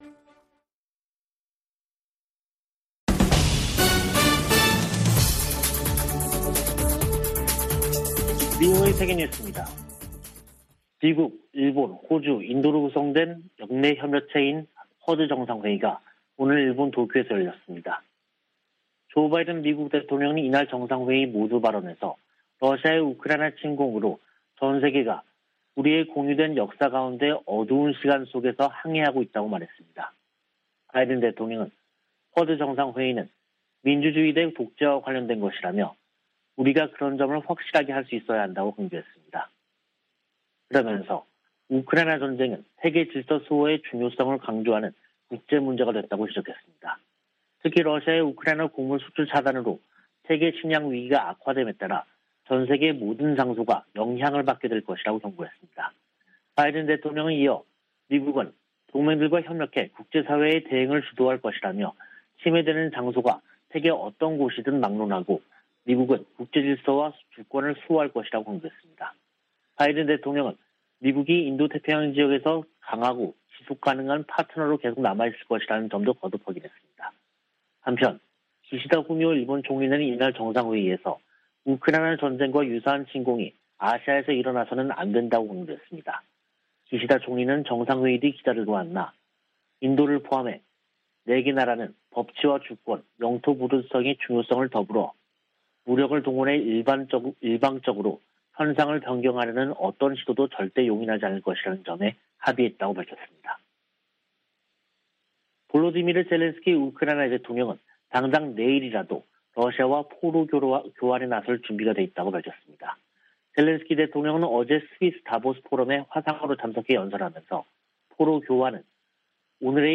VOA 한국어 간판 뉴스 프로그램 '뉴스 투데이', 2022년 5월 24일 2부 방송입니다. 도쿄에서 열린 '쿼드' 정상회의는 한반도의 완전한 비핵화 달성의지를 재확인했습니다. 미국 여야 의원들은 조 바이든 대통령이 방한 중 대규모 투자유치 성과를 냈다며, 대북 최대 압박을 복원해야 한다는 조언을 제시했습니다. 바이든 대통령은 한일 순방으로 다양한 경제, 안보 협력을 추진하면서 중국의 압박을 차단하는 효과를 거두고 있다고 미국 전문가들이 평가했습니다.